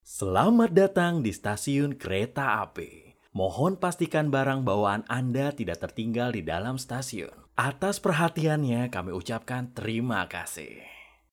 The description in is voice is clear and friendly but can alaso feels like a soft-seller if needed.